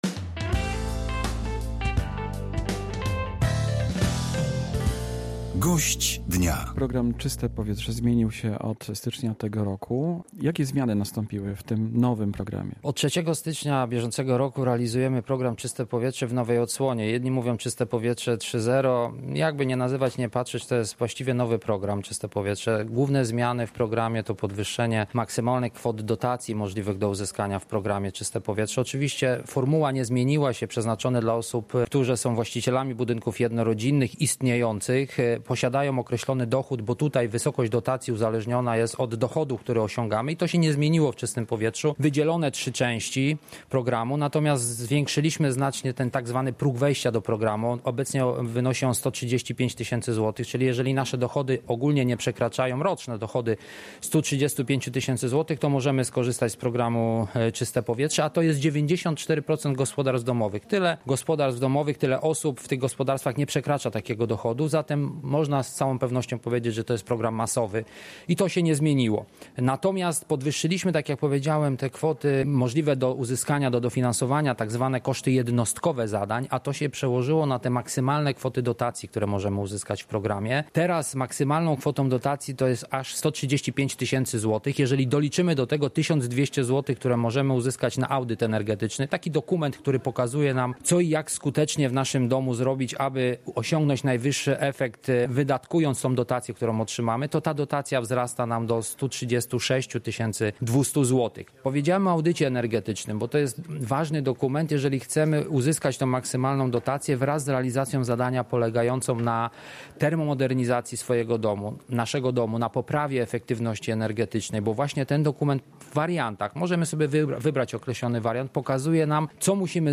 Rozmowa z Pawłem Mirowskim o programie Czyste Powietrze
– Chodzi o podwyższenie maksymalnych kwot dotacji, o które można wnioskować i zwiększony próg zakwalifikowania się do programu – powiedział na antenie Polskiego Radia Rzeszów Paweł Mirowski, zastępca prezesa zarządu Narodowego Funduszu Ochrony Środowiska i Gospodarki Wodnej.